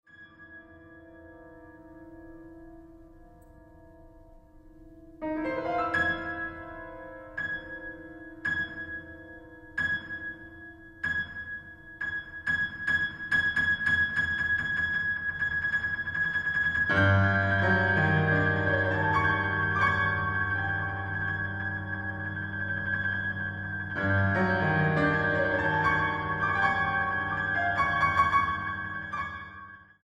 pianista
Música Mexicana para Piano